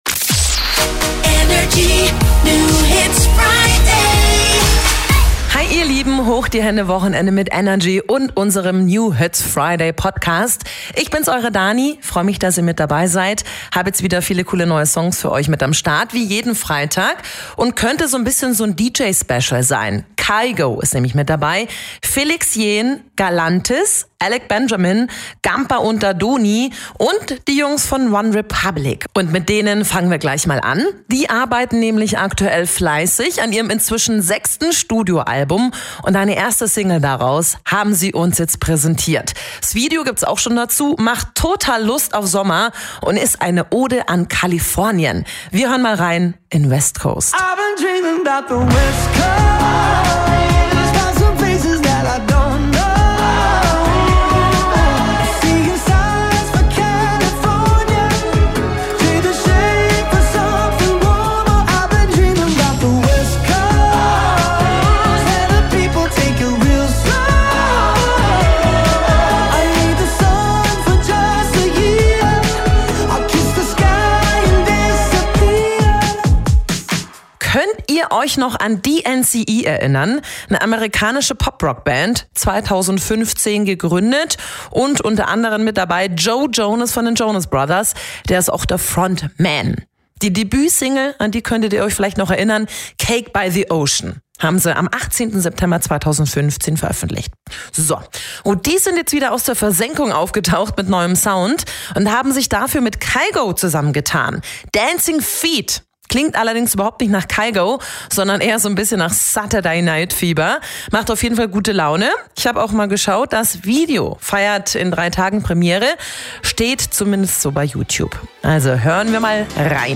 stellt brandneue hitverdächtige Songs vor.